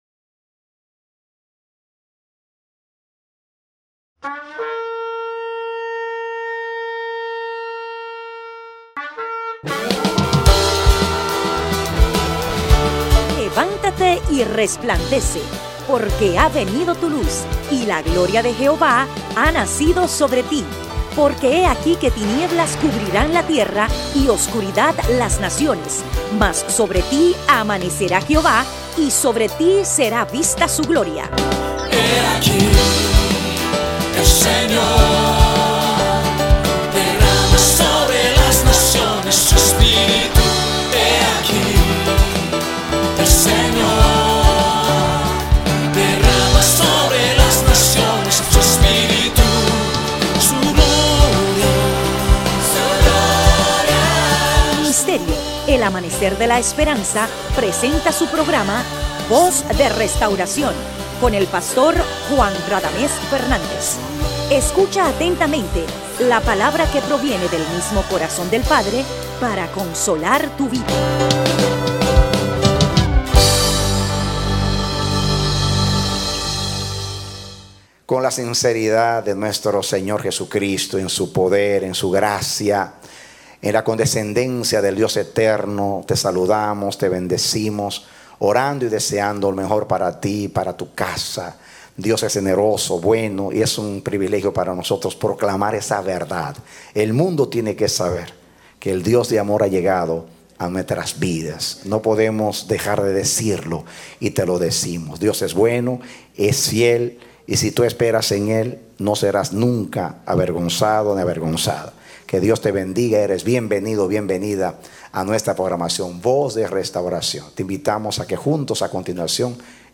Predicado Jueves Julio 16, 2015